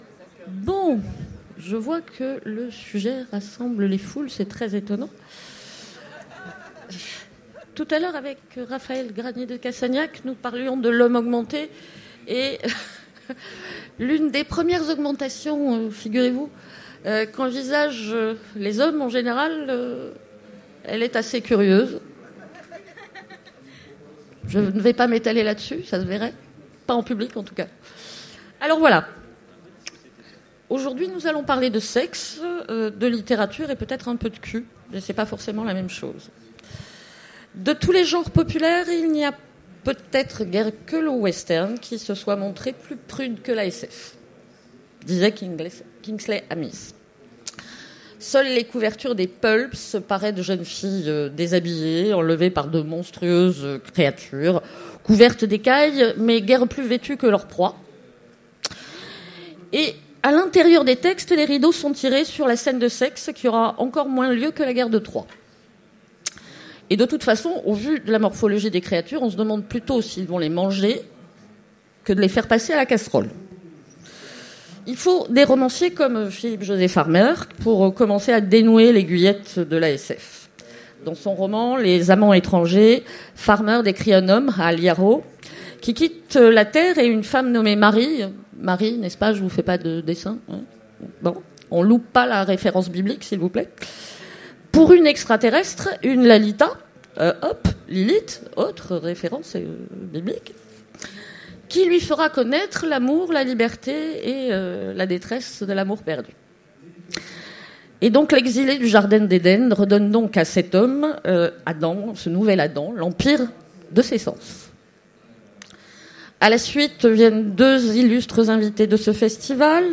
Utopiales 2015 : Conférence Sexe et SF